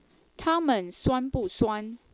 so even the male dialogue has female voice.